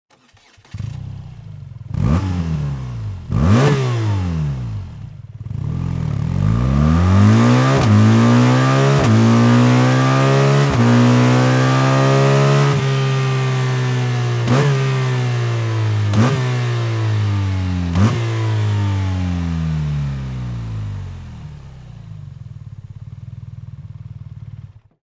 Son avec l’échappement AKRAPOVIC :
Slip-On-BMW-R12.wav